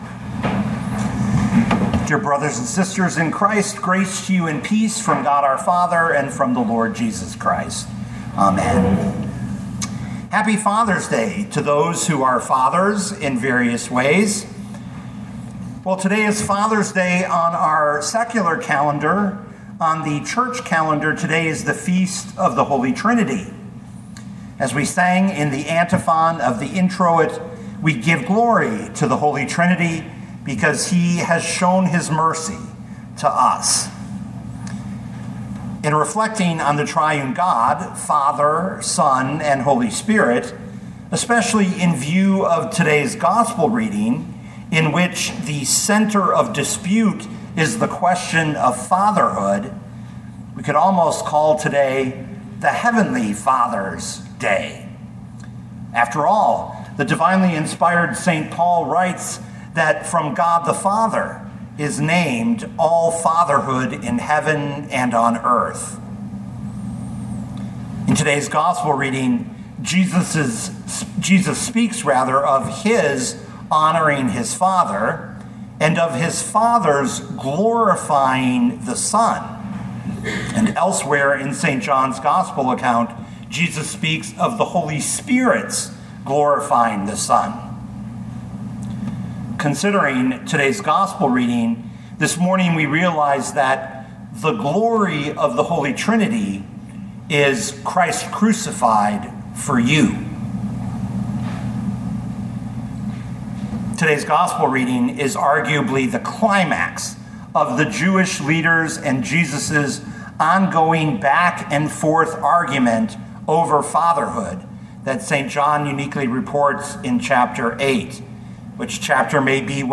2025 John 8:48-59 Listen to the sermon with the player below, or, download the audio.